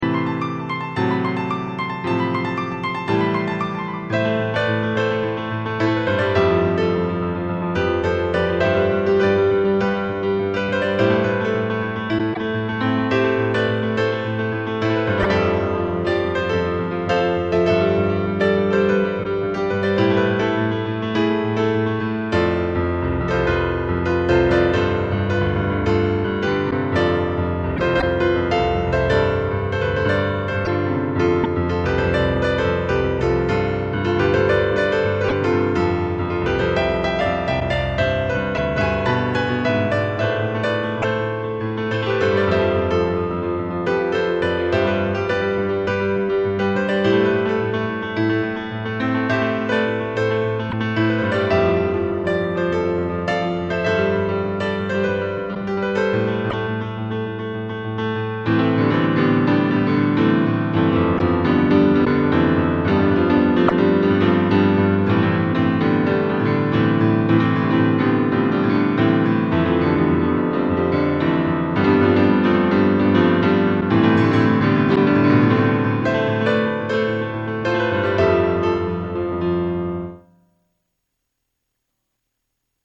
Piano
戦いっぽい曲1
P.Sまぁ音が悪いのは、レコードのせいだと好意的に解釈してください。